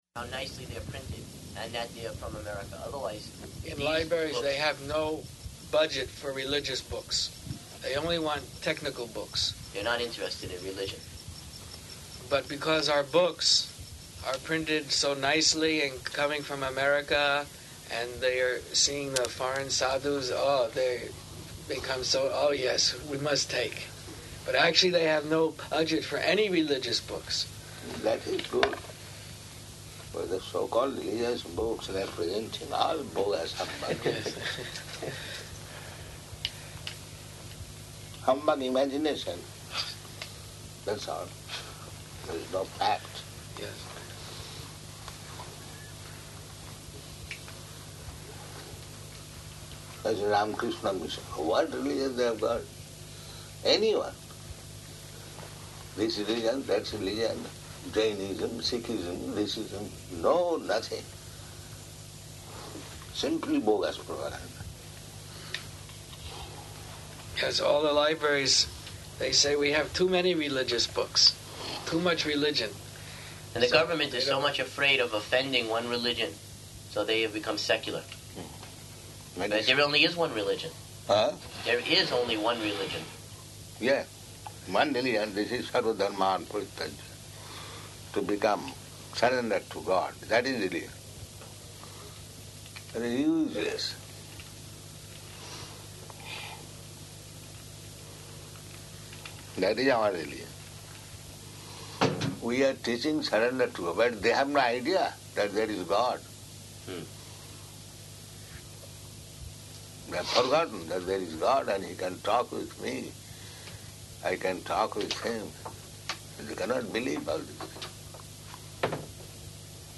Room Conversation
-- Type: Conversation Dated: January 21st 1977 Location: Bhubaneswar Audio file